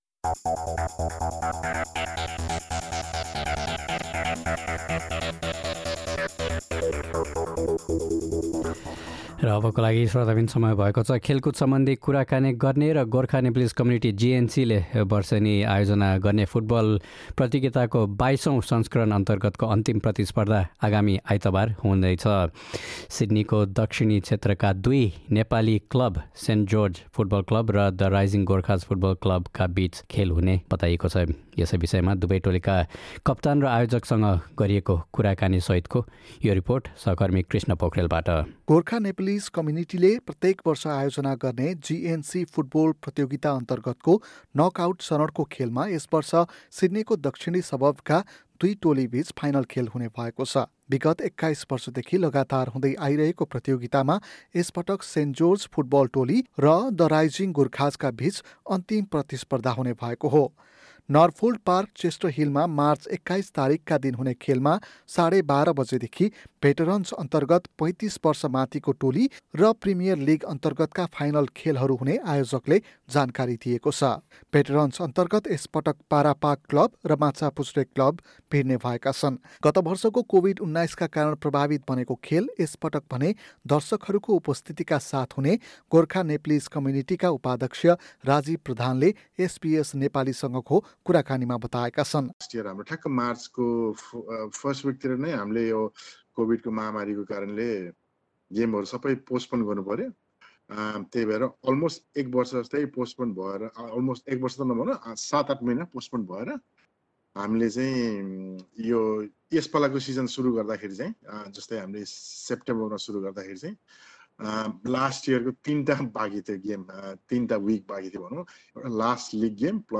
हाम्रो कुराकानी सुन्न माथीको मिडिया प्लेयरमा प्ले बटन थिच्नुहोस्!